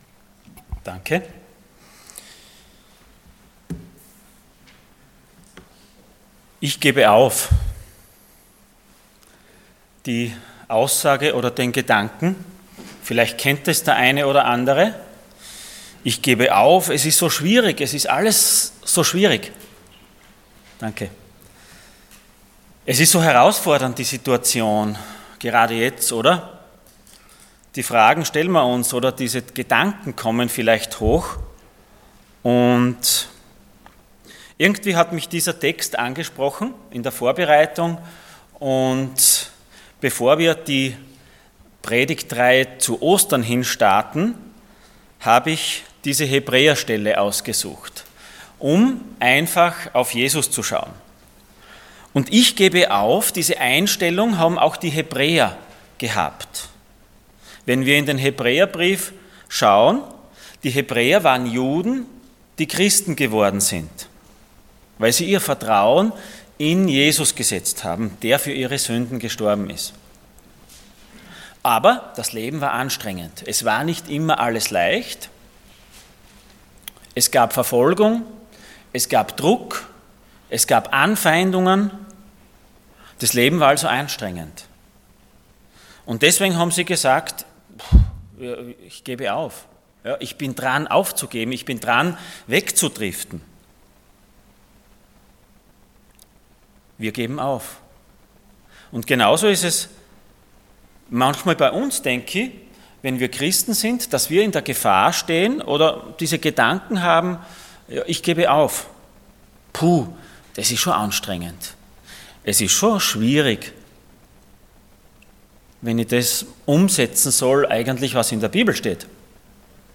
Passage: Hebrews 11:39-12:13 Dienstart: Sonntag Morgen